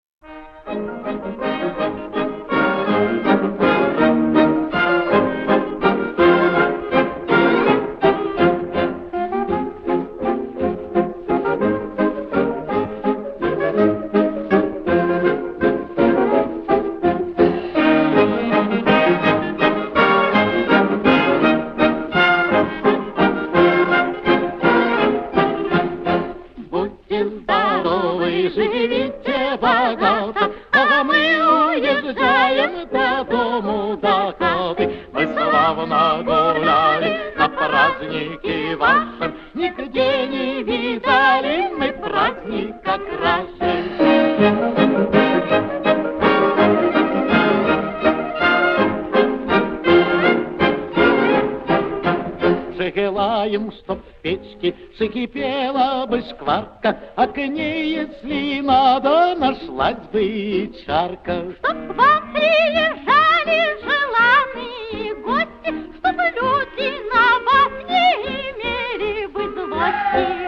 Фрагмент песни